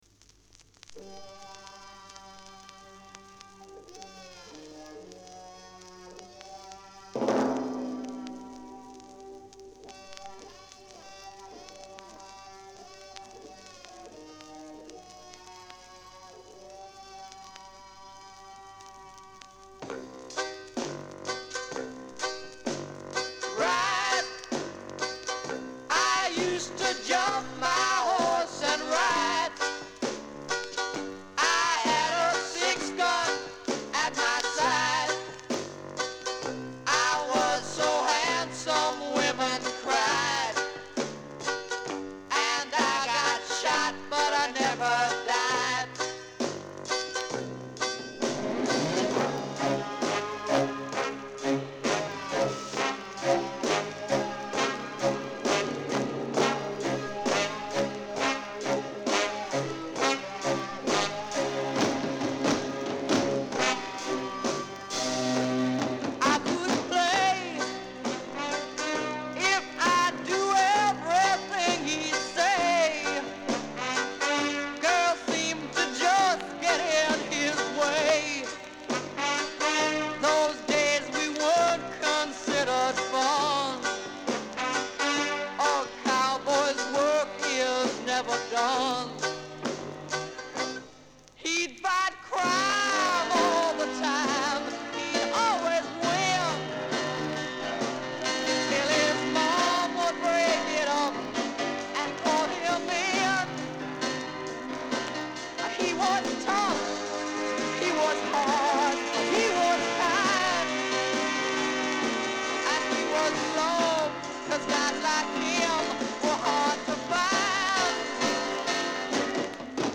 Качество среднее но лучше не нашел